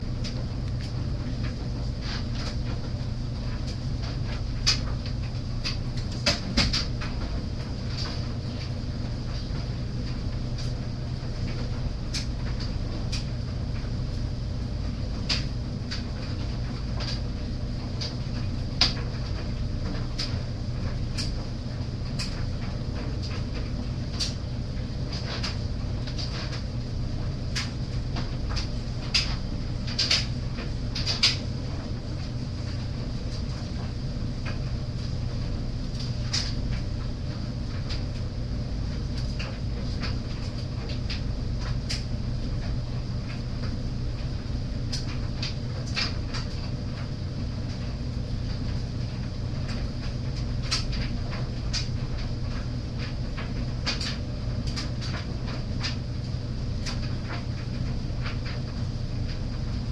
SOUND CLIP: Republic Hall Laundry Room LOCATION: Republic Hall laundry room SOUNDS HEARD: hum from dryer, clothes tossing, zippers/metal hitting dryer drum, door open, door close